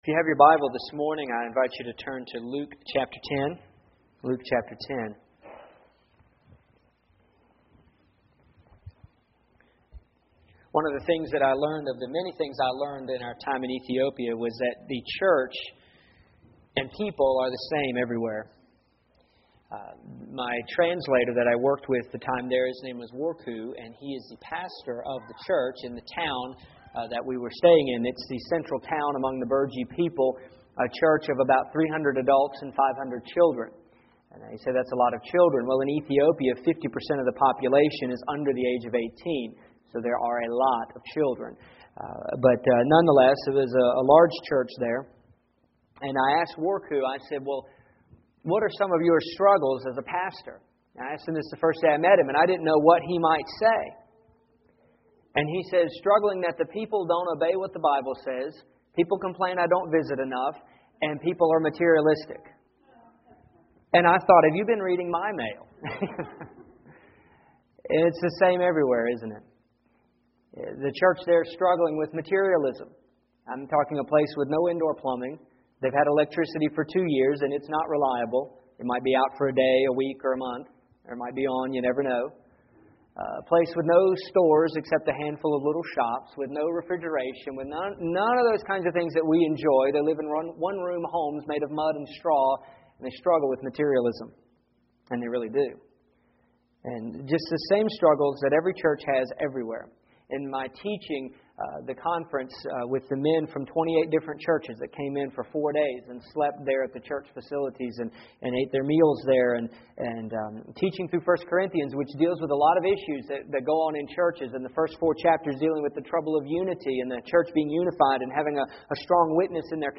Here is my sermon from December 14th.